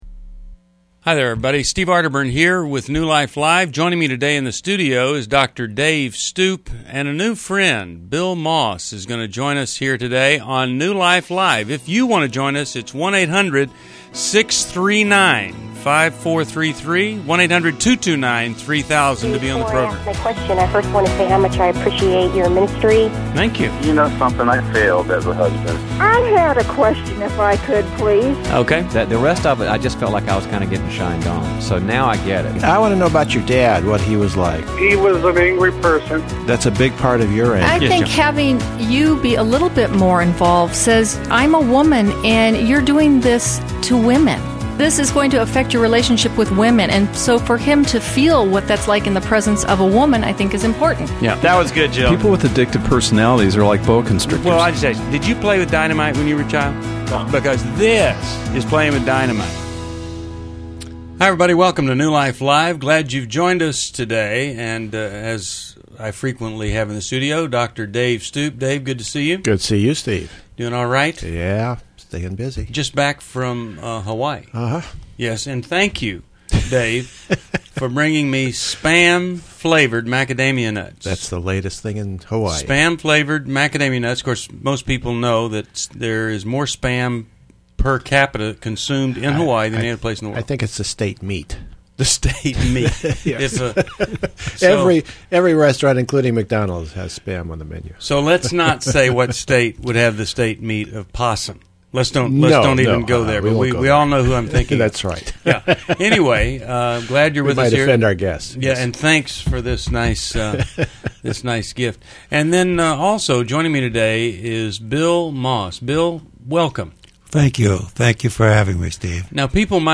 Caller Questions: 1. How can I get my husband to stop his serial affairs? 2. Why am I preoccupied with getting married at 32 years old? 3. My wife asked for a divorce after not talking for a year; what can I do? 4. I have anxiety and depression; should I go with God or do therapy and meds?